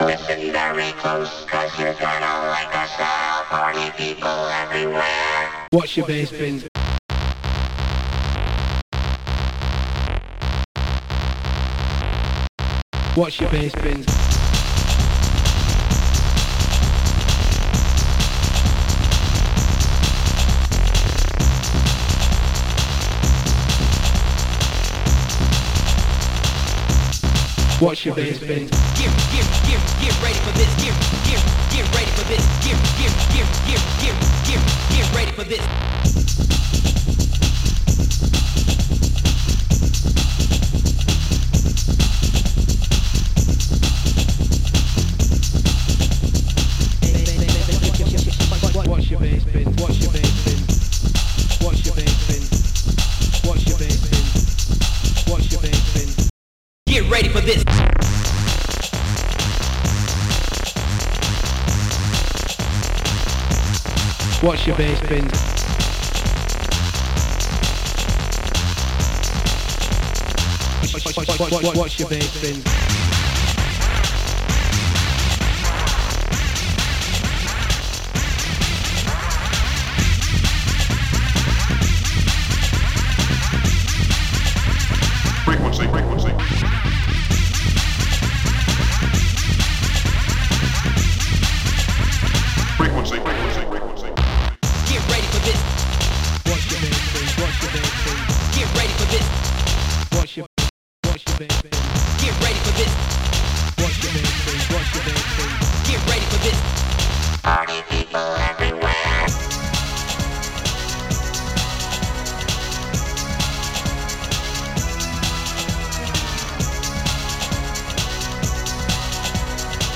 Protracker M.K.
people of the rave